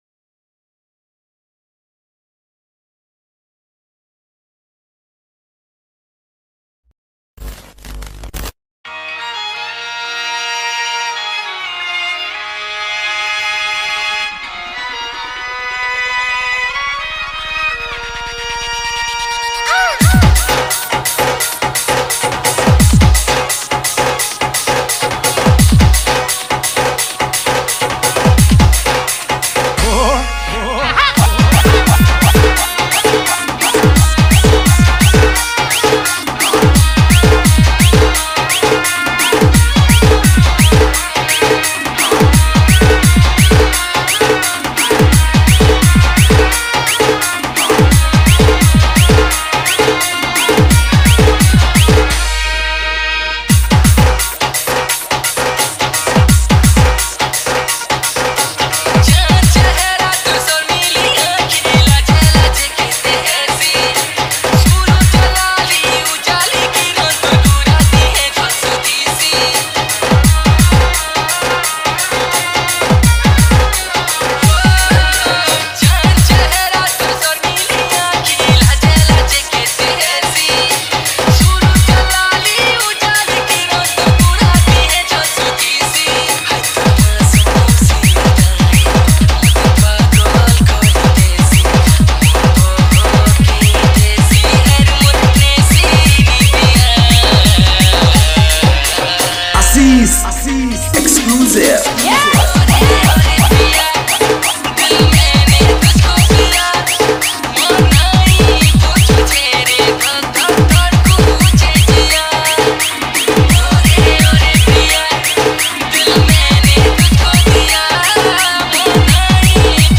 Category:  Sambalpuri Dj Song 2021